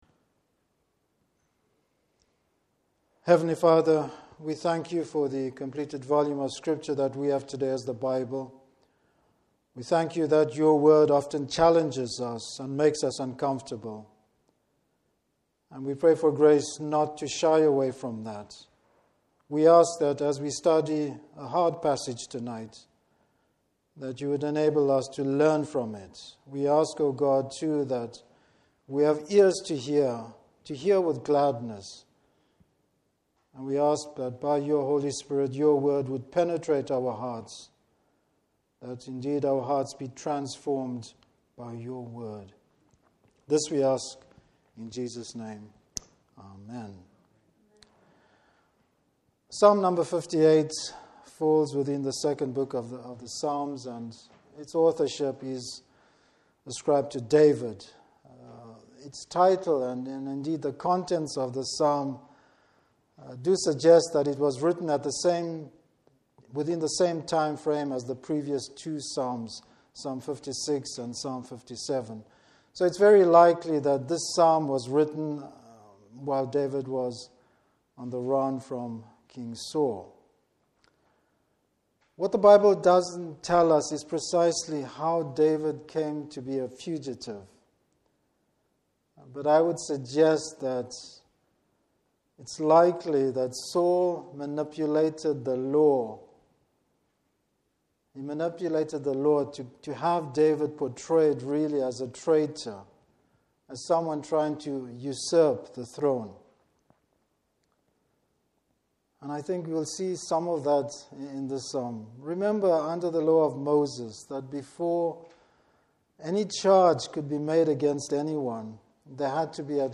Service Type: Evening Service David looks to the Lord for justice.